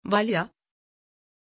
Pronunciation of Valya
pronunciation-valya-tr.mp3